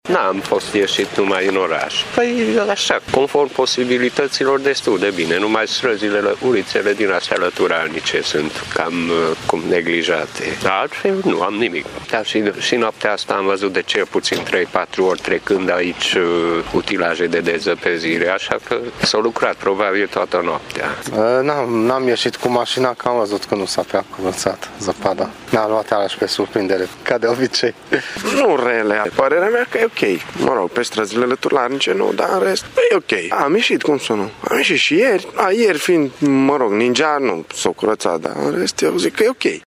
Șoferii au evitat să iasă ieri în trafic. Ei spun că problemele cele mai mari sunt pe străzile lăturalnice, unde utilajele de deszăpezire nu au reușit să intervină.